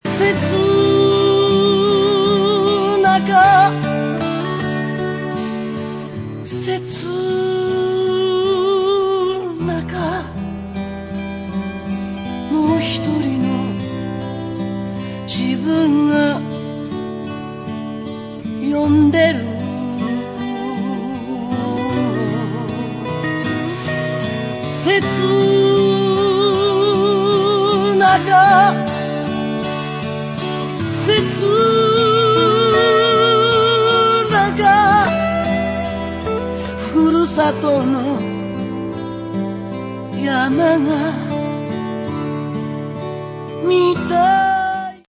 オリジナルアルバム
アコースティックな音色が胸にやさしく響いてくる味わいがたまりません。